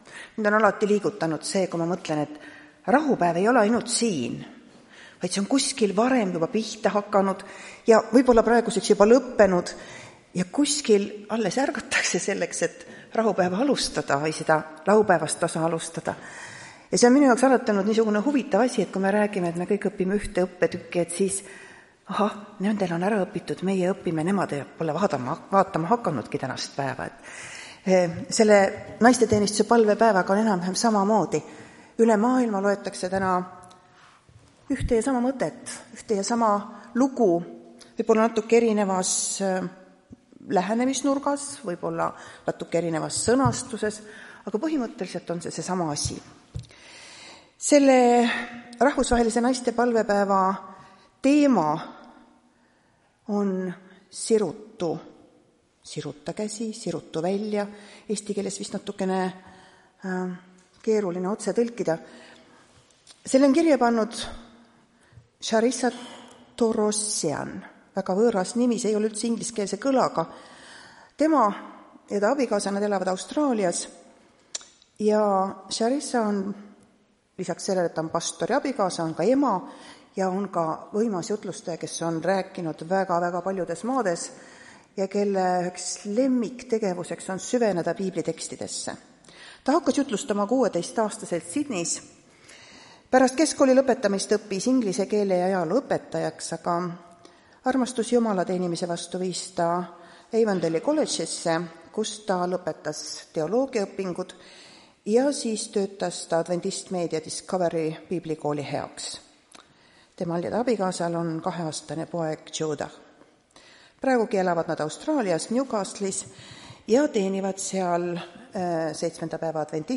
Tartu adventkoguduse 01.03.2025 hommikuse teenistuse jutluse helisalvestis.